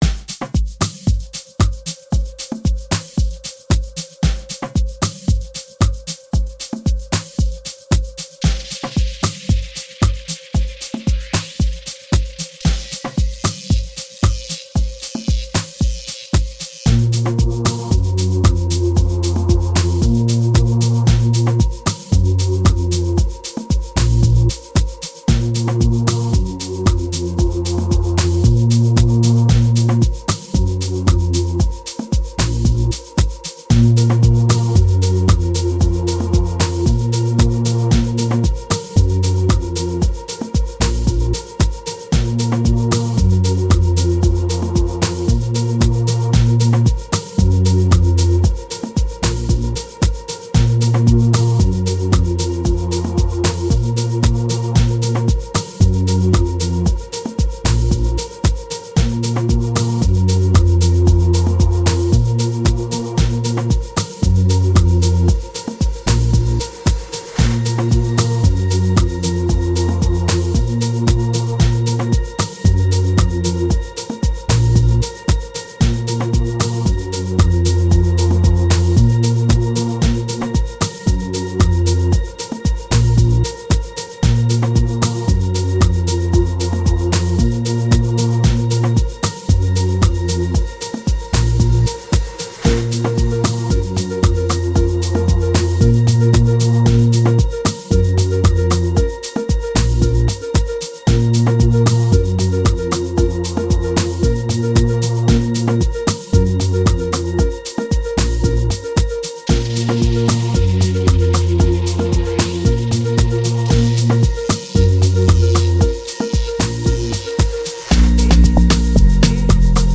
05:56 Genre : Amapiano Size